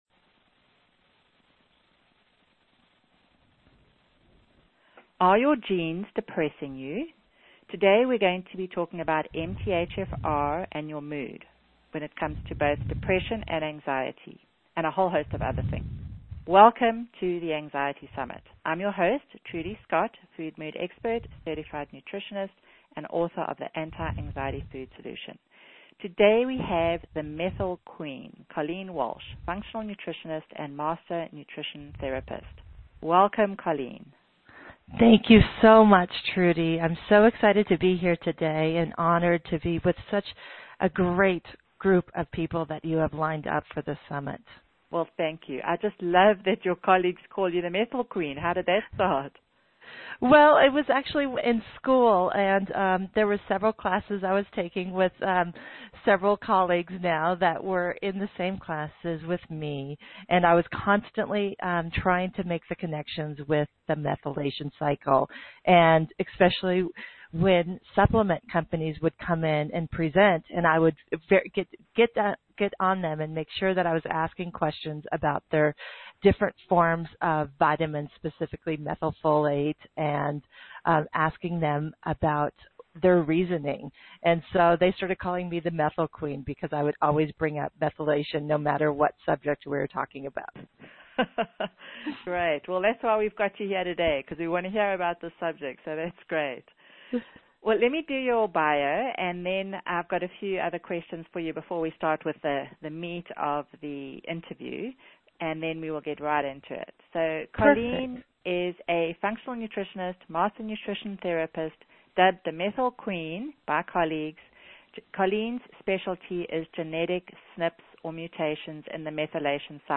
MTHFR workshop for the layperson